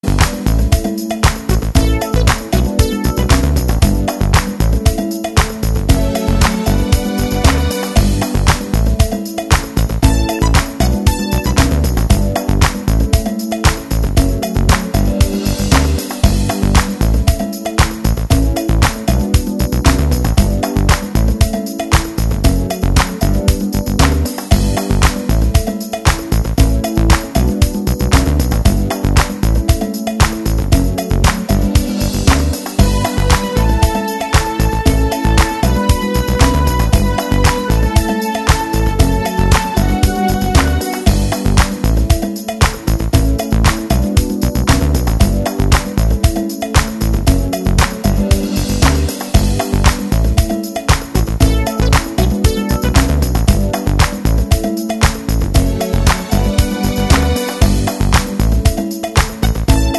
No Backing Vocals Or Guitars Pop (1980s) 4:12 Buy £1.50